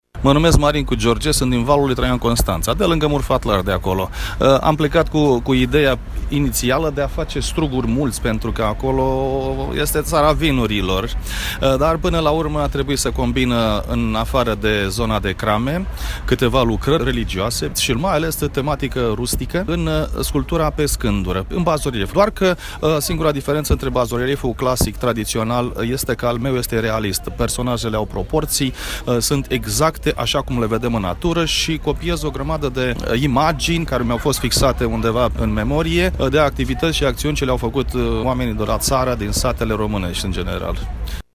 Artiști populari din toată țara la Târgul meșteșugăresc de la Tg.Mureș
Un alt meșter popular a venit tocmai din județul Constanța pentru a-și expune produsele: